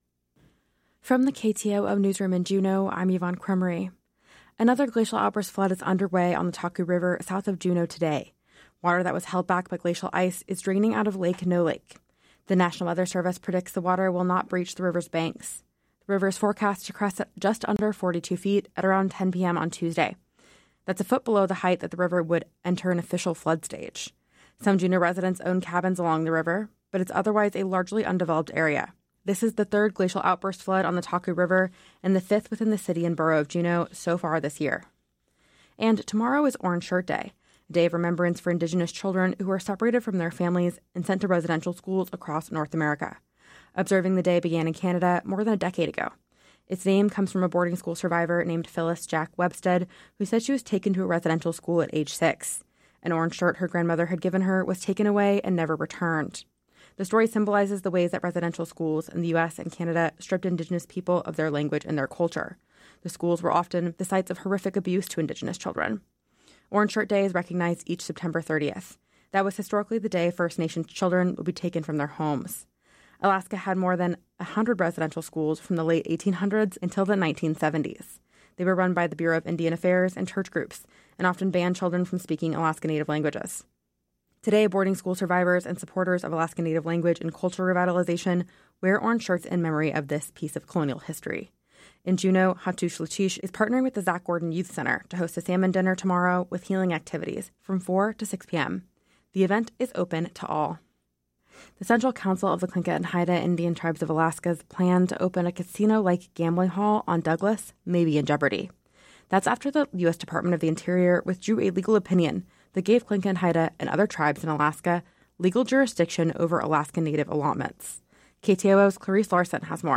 Newscast – Monday, Sept. 29, 2025